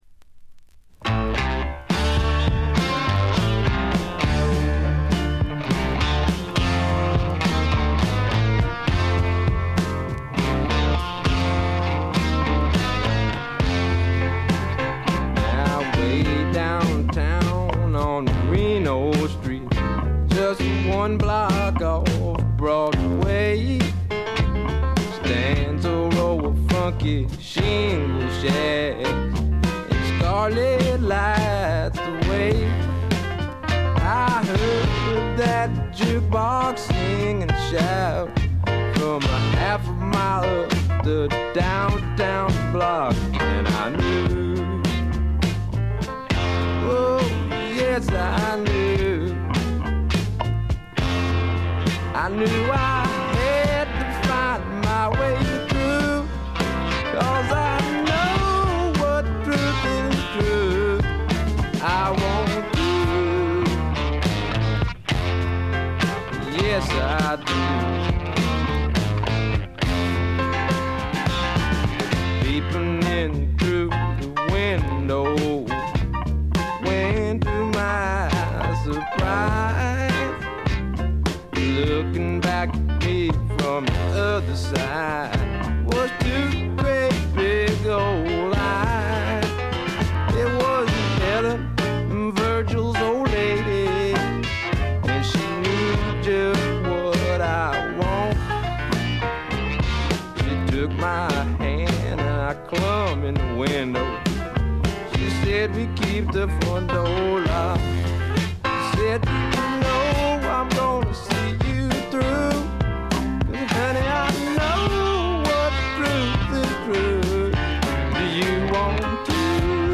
ごくわずかなノイズ感のみ。
まさしくスワンプロックの真骨頂。
それにしてもこのベースの音は尋常ではない凄みがあります。
試聴曲は現品からの取り込み音源です。
Vocal, Guitar, Keyboards